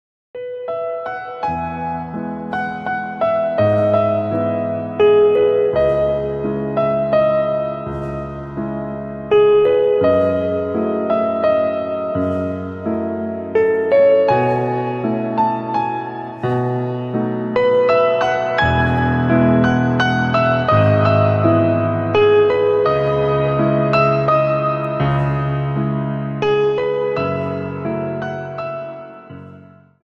Dance: Slow Waltz 28 Song